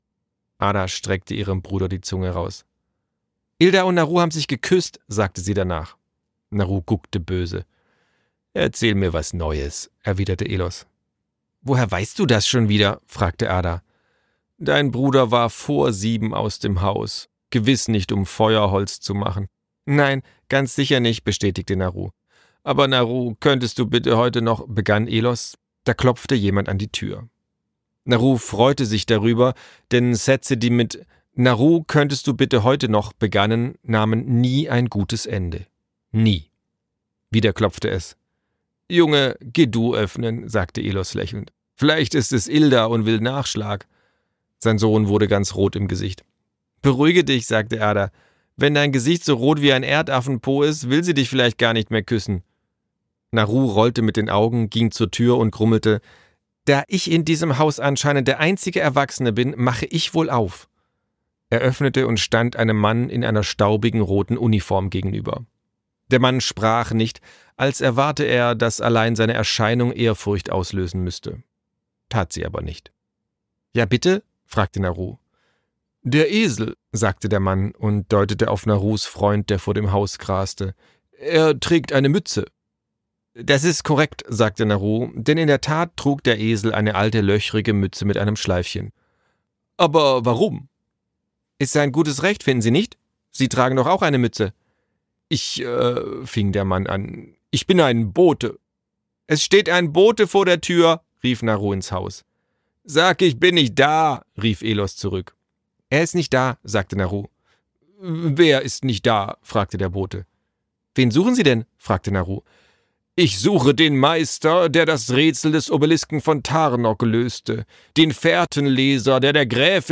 Sprecher Marc-Uwe Kling